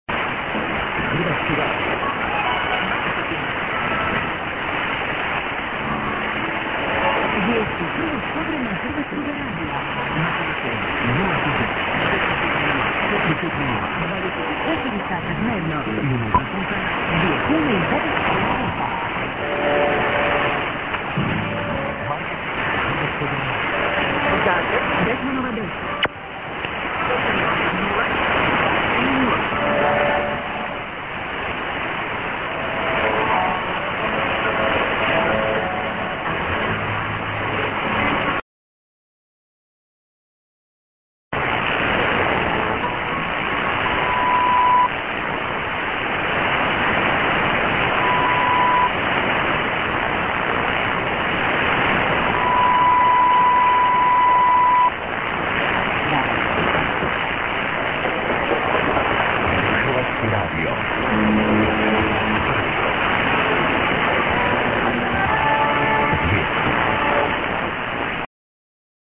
03/04/20 01:56 13,830　 143　 poor
ID:Croatia R.(man:)->music->TS->ID(man)
信号が弱いのでヘッドホーンを利用して聞いてください。途中の音楽の一部がカットしてあります。